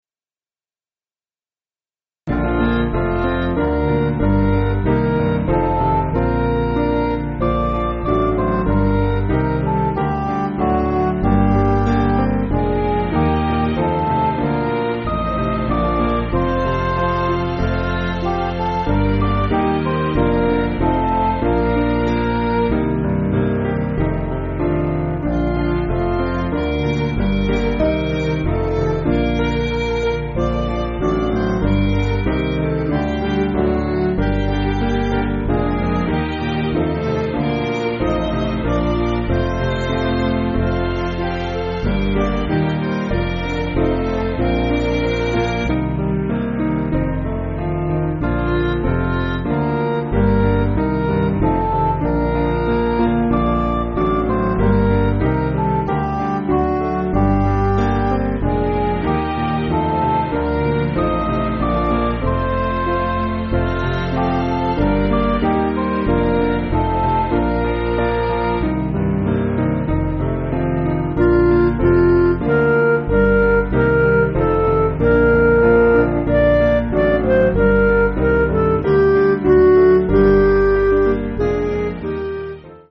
Piano & Instrumental
(CM)   4/Bb